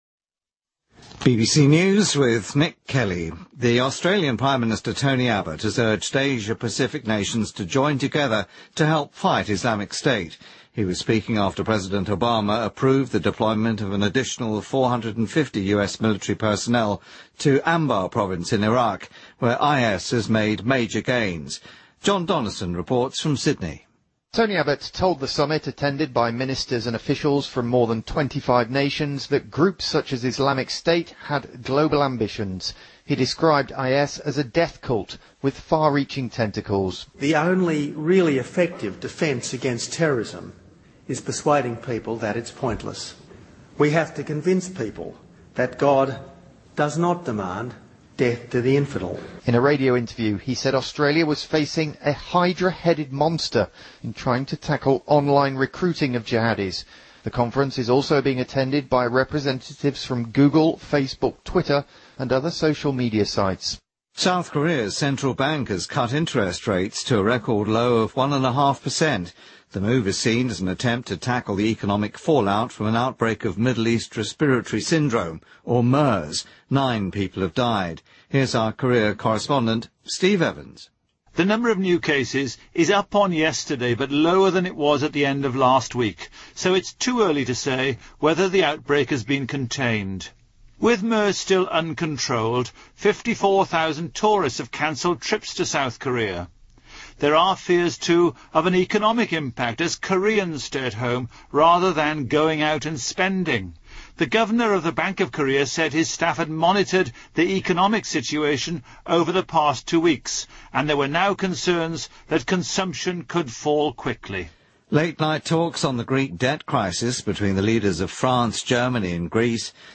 BBC news,中东呼吸综合征(MERS)疫情迫使韩国央行降低利率